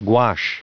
Prononciation du mot gouache en anglais (fichier audio)
Prononciation du mot : gouache